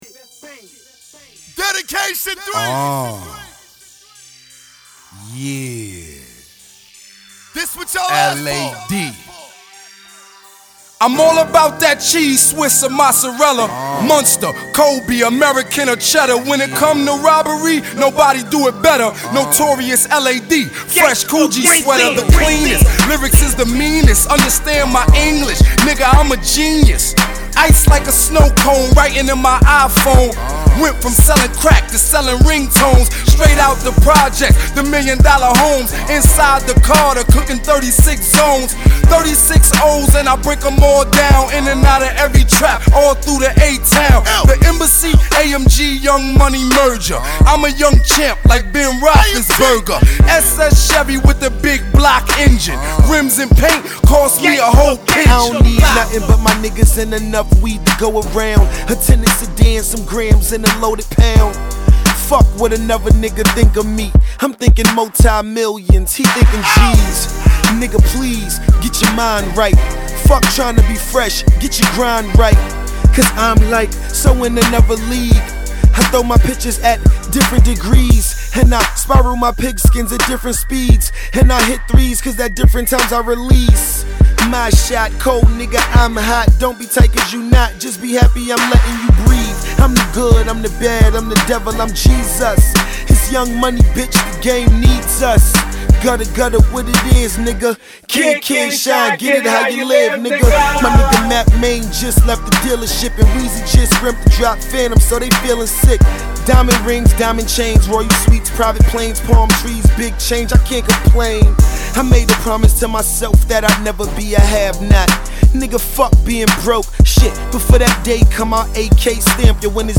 Rap/Hip Hop